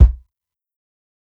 KICK_DANCE_WIT_ME.wav